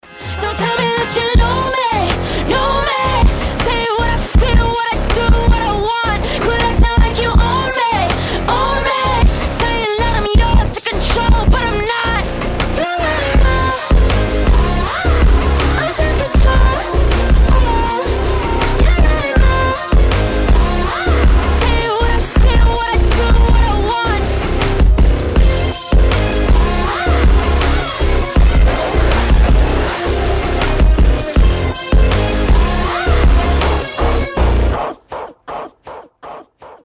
мощные
alternative
Electronic Rock